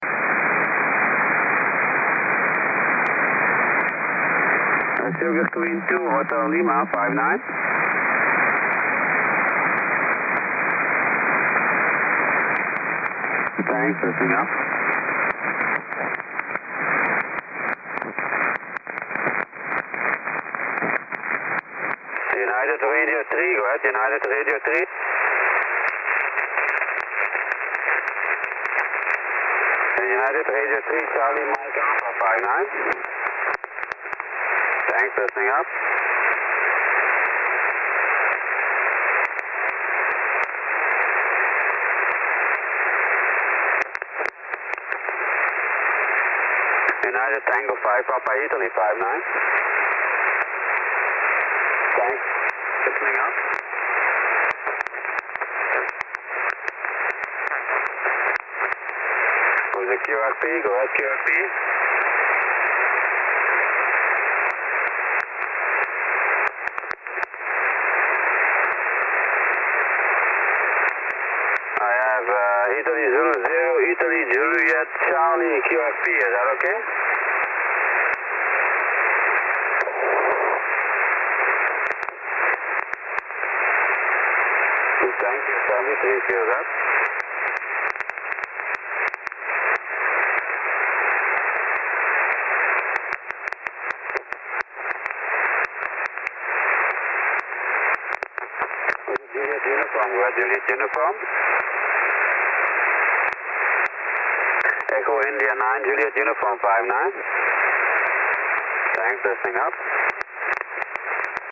TY1TT 12M SSB